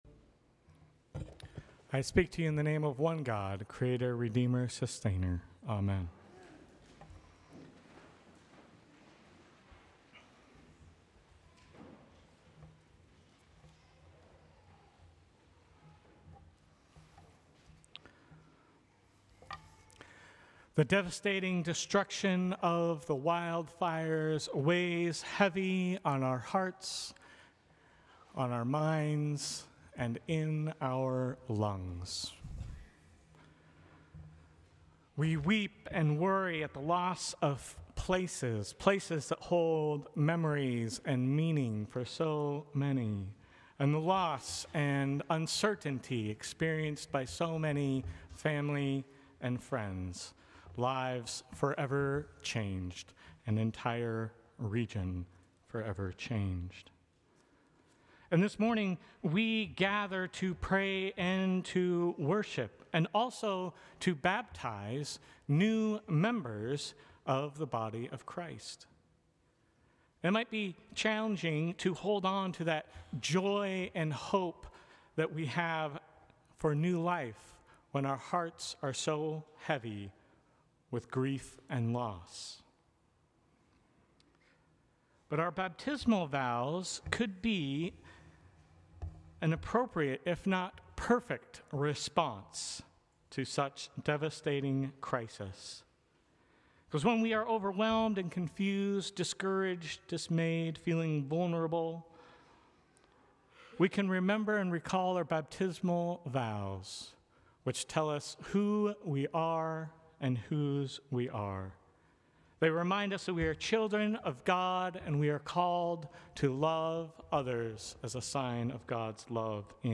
Sermons from St. Cross Episcopal Church First Sunday after the Epiphany Jan 12 2025 | 00:11:32 1x 00:00 / 00:11:32 Subscribe Share Apple Podcasts Spotify Overcast RSS Feed Share Link Embed